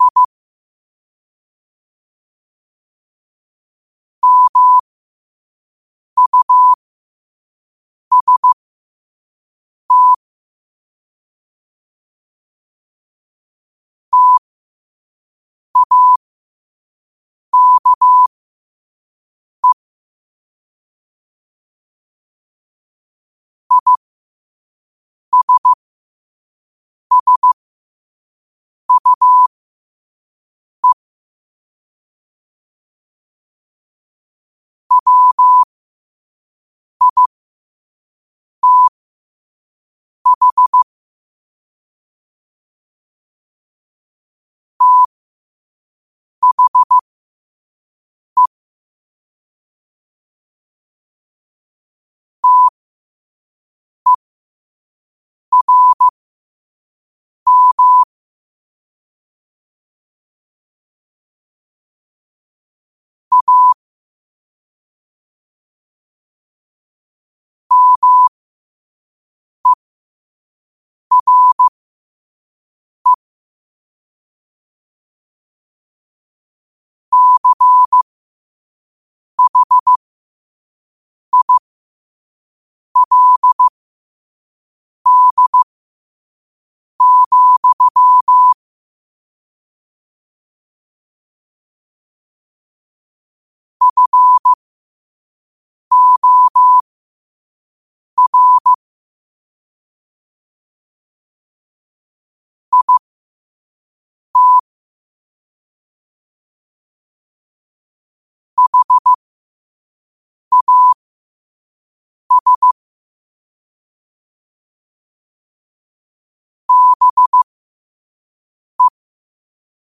New quotes every day in morse code at 5 Words per minute.